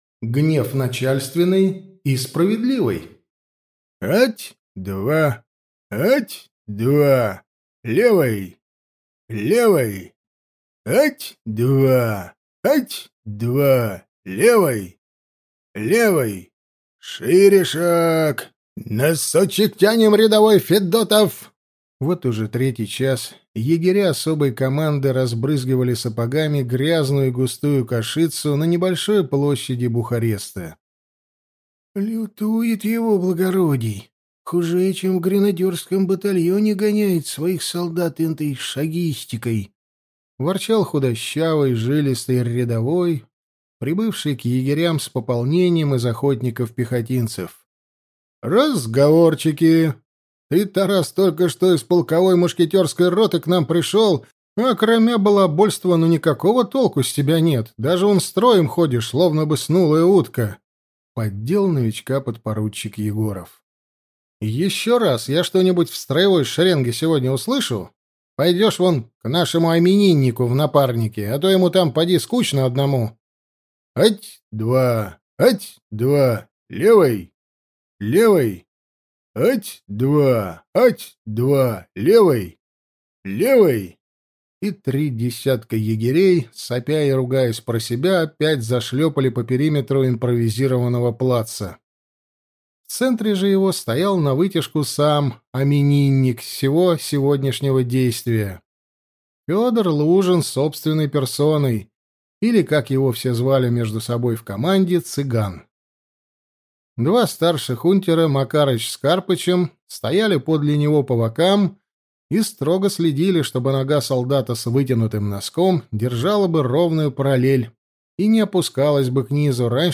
Аудиокнига Егерь императрицы. Тайная война | Библиотека аудиокниг